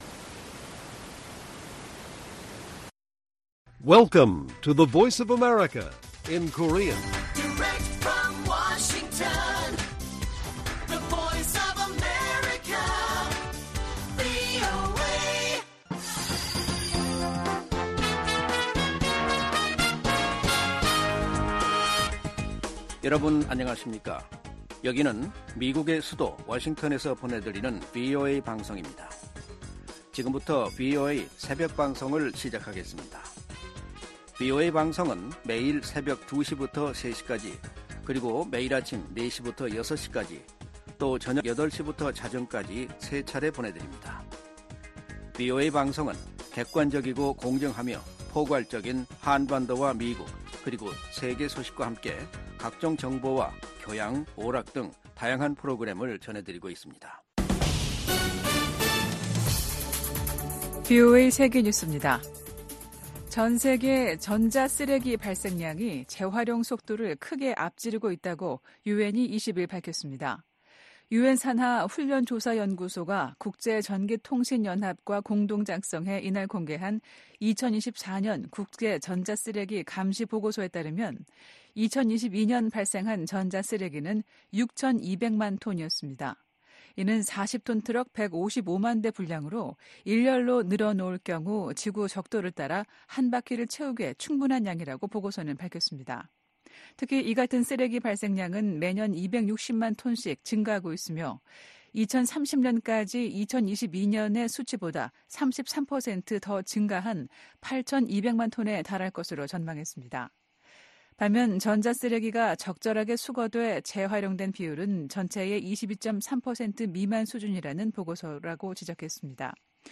VOA 한국어 '출발 뉴스 쇼', 2024년 3월 22일 방송입니다. 북한이 영변 핵시설에서 핵탄두 소형화에 필수적인 삼중수소 생산 시설을 가동 중이라는 위성사진 분석 결과가 나왔습니다. 북한이 러시아와 관계를 강화하면서 국제 질서를 위협하고 있다고 주한미군사령관이 지적했습니다. 블라디미르 푸틴 러시아 대통령의 5연임이 확정되면서 북한과 러시아 밀착에 탄력이 붙을 것이란 전망이 나옵니다.